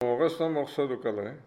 Localisation Xanton-Chassenon
Catégorie Locution